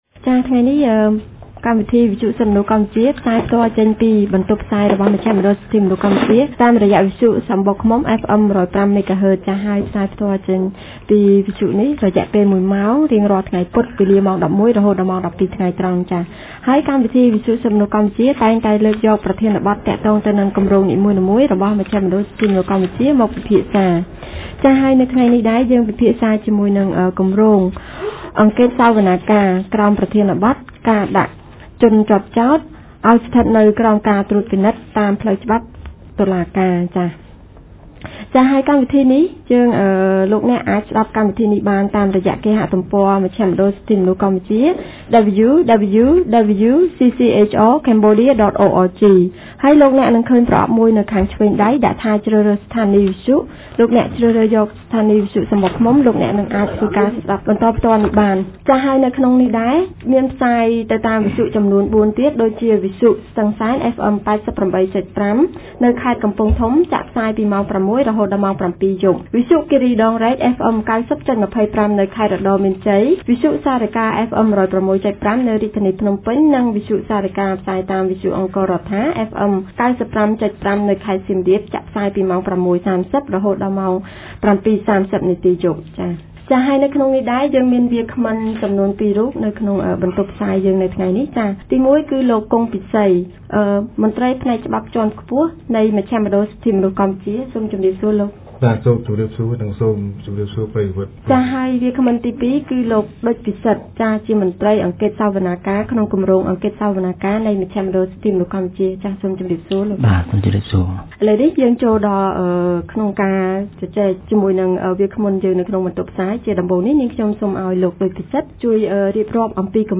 Yet, as provided by Articles 223 and 230 of the Cambodian Code of Criminal Procedure, the investigating judge can decide to place any charged person under judicial supervision as an alternative to pre-trial detention. A TMP radio talk show on the issue of judicial supervision aims at highlighting this underused criminal procedure.